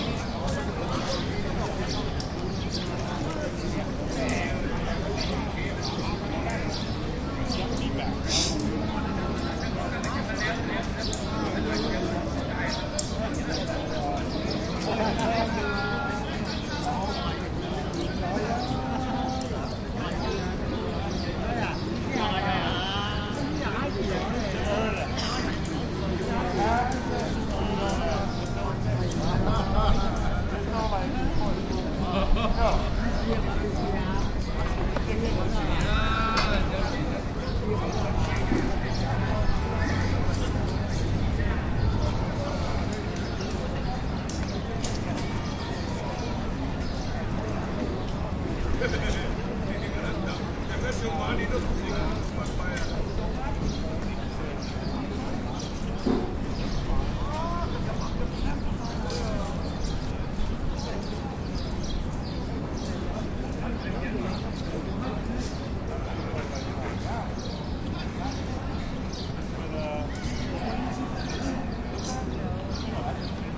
描述：夏季西瓜在中华人民共和国浙江省Monganshan出售
标签： 语音 农村 亚洲 蟋蟀 xigua 声乐 售中 中国 西瓜 水果 农场 性质 现场记录 销售 本地 浙江 冬瓜 摆卖 森林 中国 莫干山 说话 普通话 晚上 臭虫 亚洲 昆虫 夏天
声道立体声